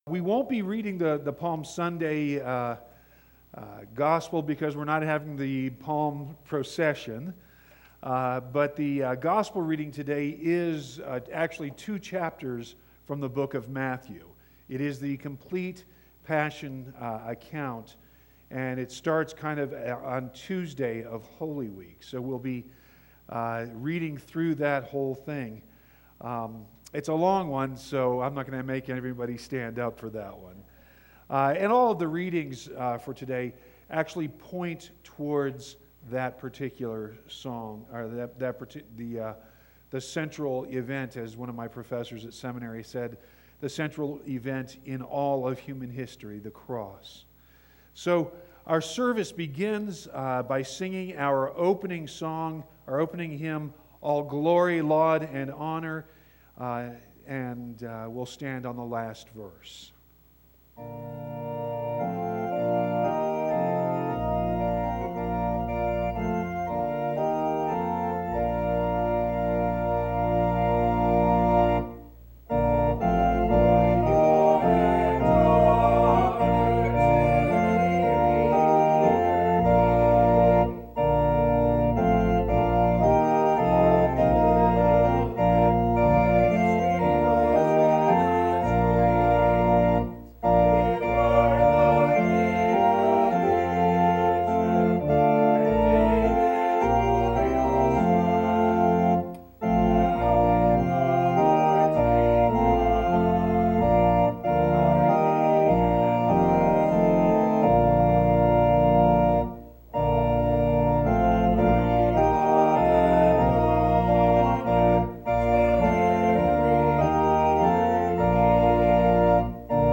You can also download the sermon directly HERE, or get all the sermons on your phone by subscribing to our Podcast HERE.